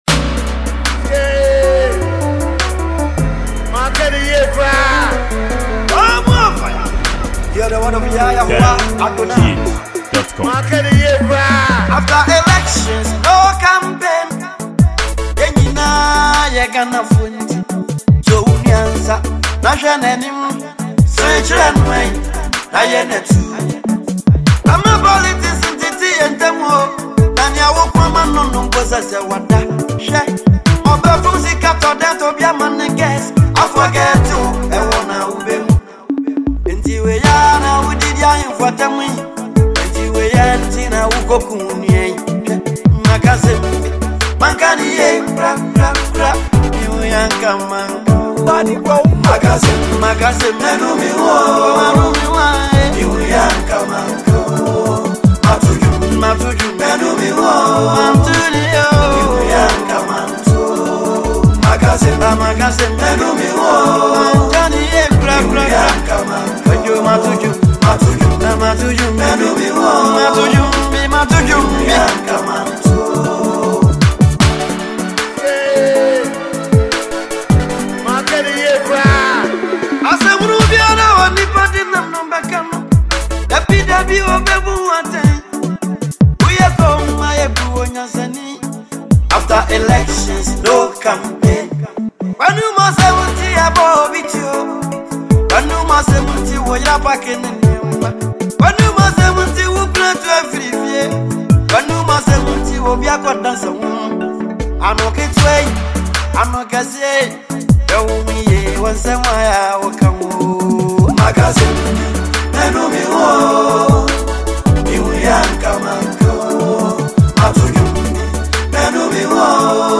Ghanaian gospel musician